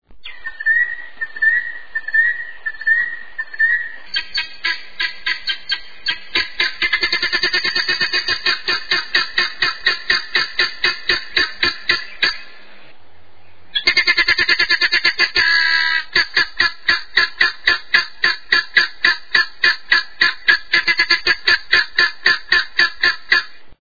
Обыкновенная цесарка (Numida meleagris).
cesarka-numida-meleagris.mp3